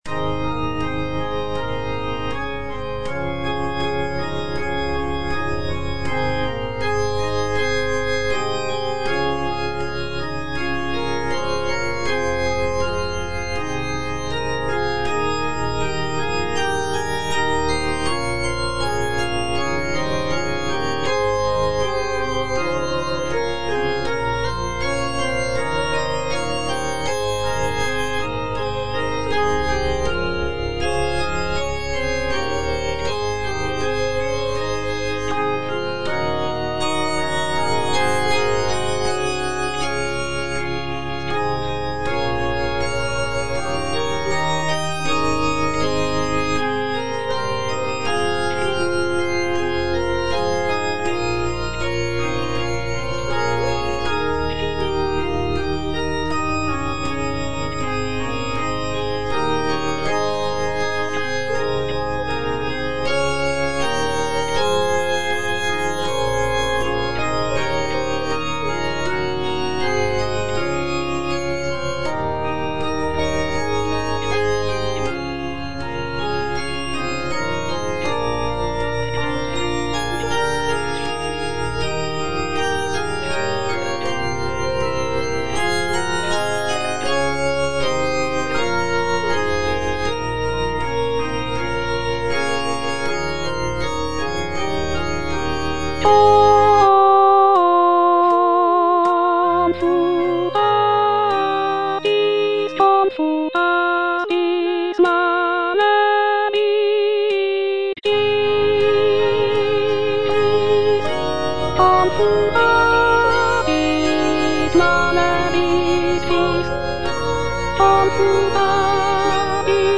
Soprano (Voice with metronome) Ads stop
is a sacred choral work rooted in his Christian faith.